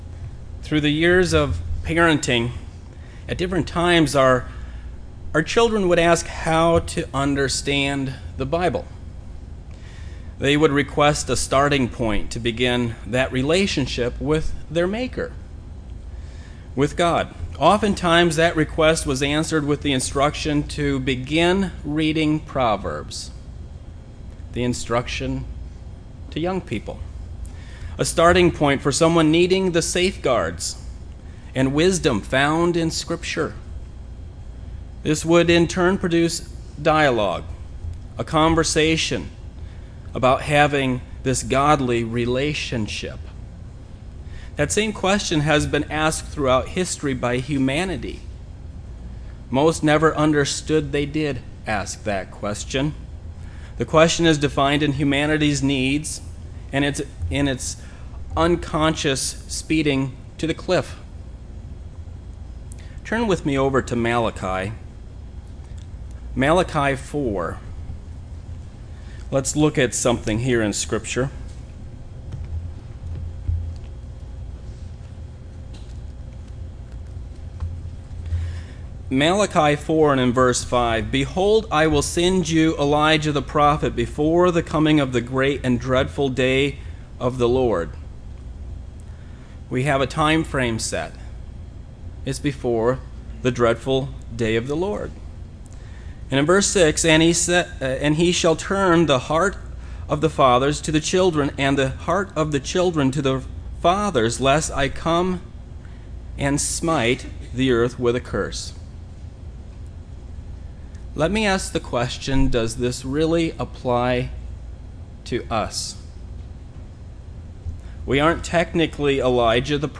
UCG Sermon Studying the bible?
Given in Beloit, WI